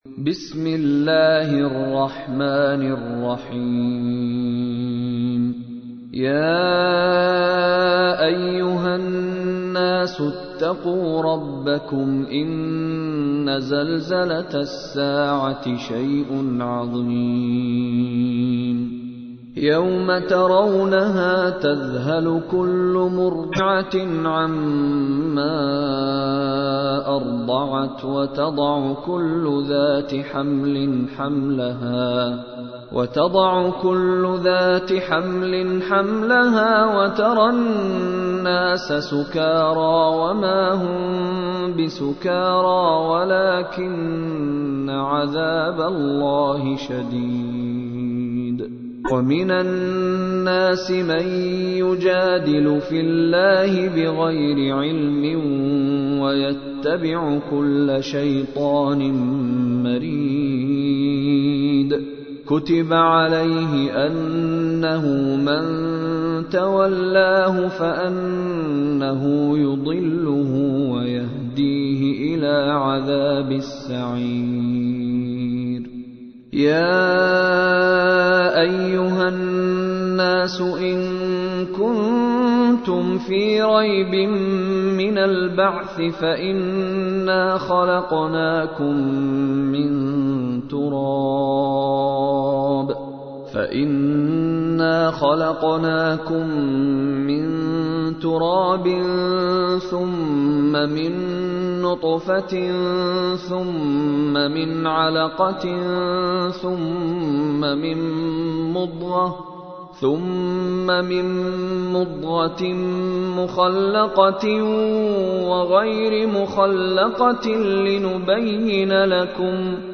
سورة الحج / القارئ مشاري راشد العفاسي / القرآن الكريم / موقع يا حسين